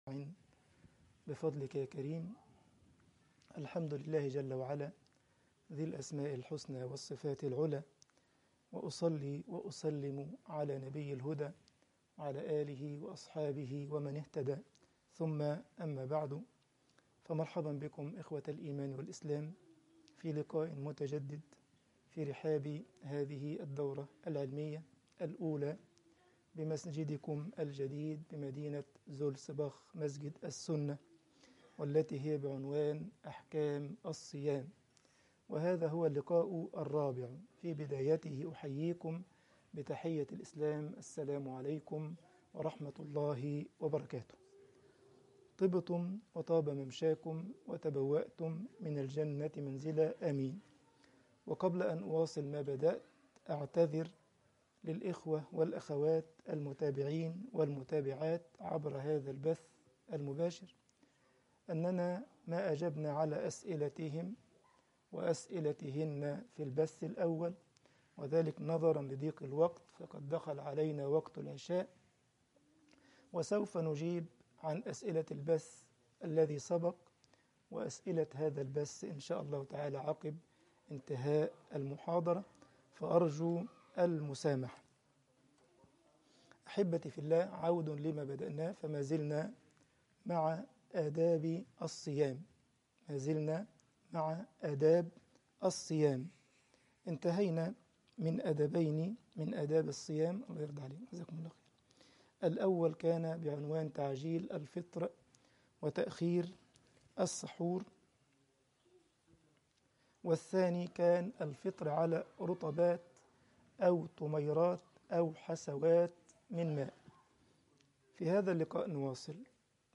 الدورة العلمية رقم 1 أحكام الصيام المحاضرة رقم 4